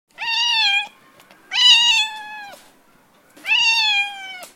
دانلود صدای گربه برای زنگ موبایل از ساعد نیوز با لینک مستقیم و کیفیت بالا
جلوه های صوتی